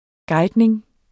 Udtale [ ˈgɑjdneŋ ]